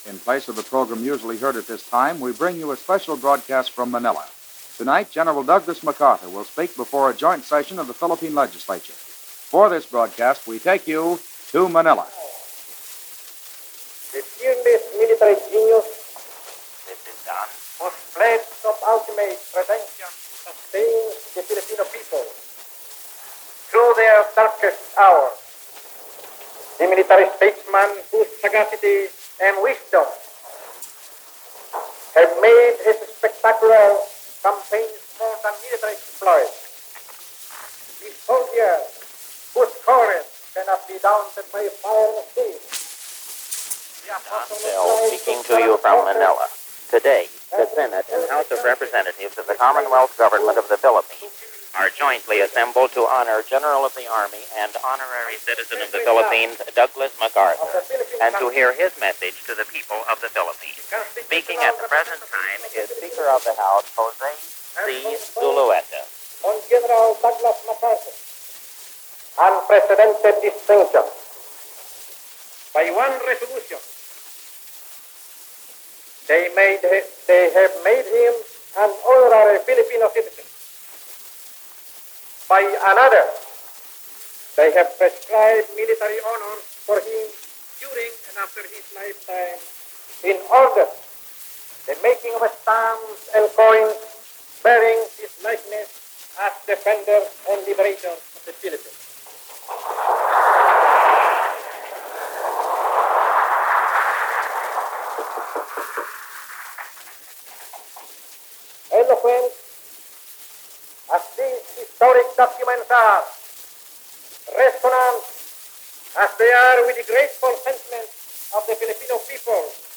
General Douglas MacArthur Addresses The Manila Legislature - July 8(9) - 1945 - Past Daily Reference Room - Live Broadcast by Mutual.
Making good on his promise to return to Manila, General Douglas MacArthur addresses the Legislature in Manila for the first time since the beginning of the War.